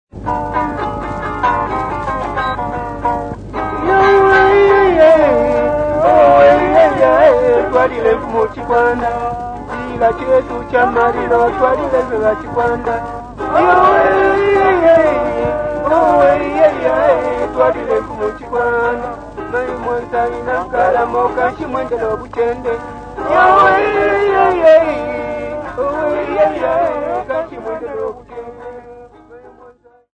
Folk Music
Field recordings
Africa, Sub-Saharan
sound recording-musical
Indigenous music
Vestax BDT-2500 belt drive turntable